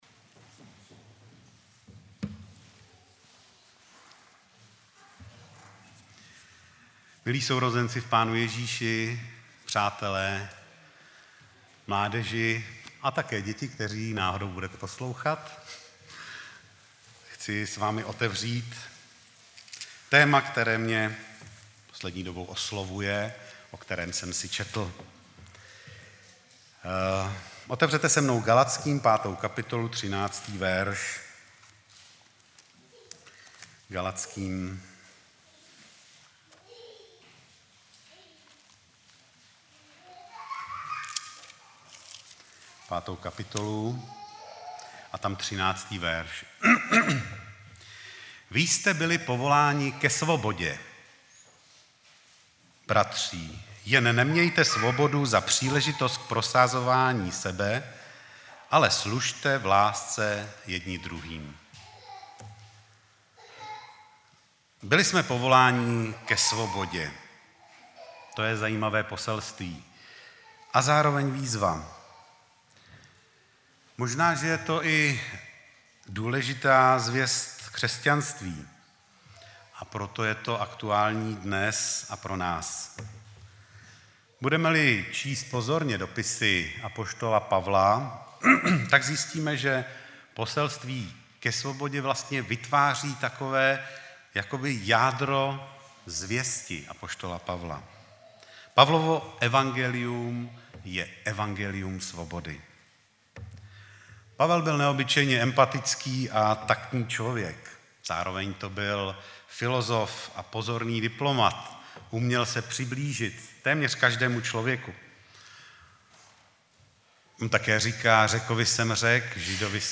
10.12.2016 v 17:44 do rubriky Kázání .